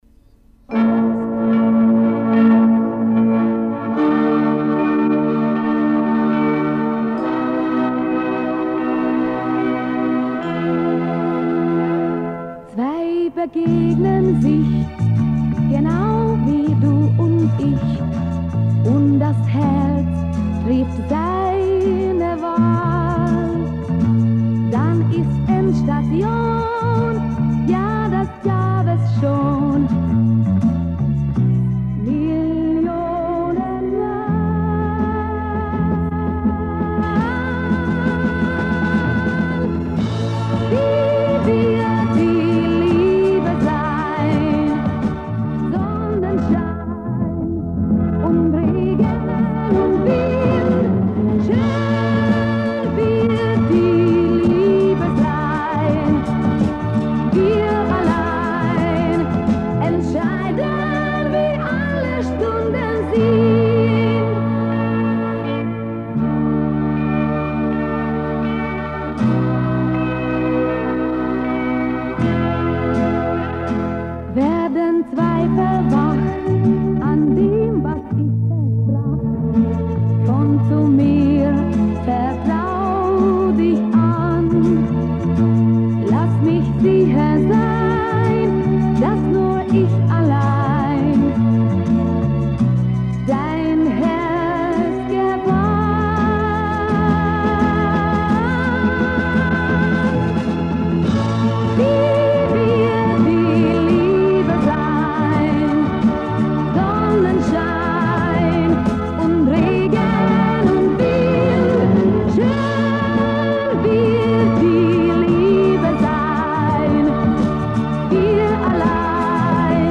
Rundfunkaufnahme (Запись трансляции)